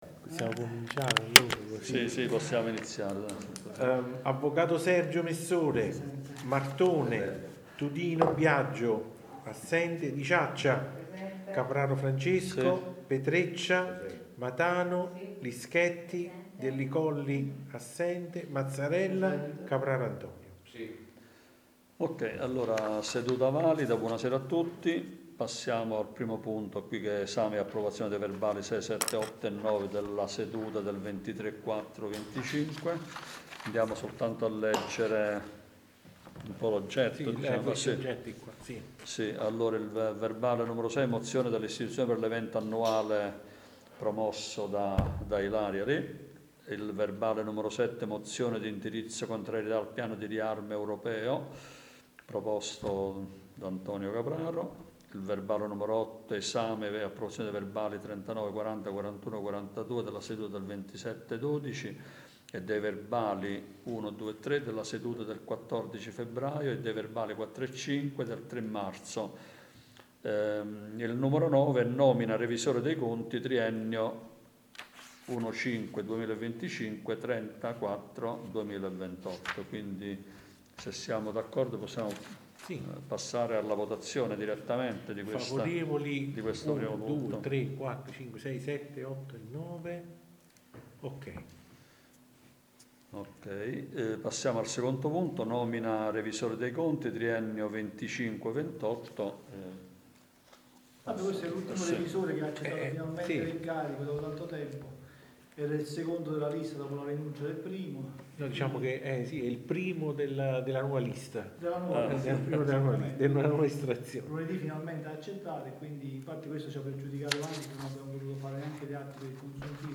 Registrazione seduta consiliare del 13.05.2025
Consiglio Comunale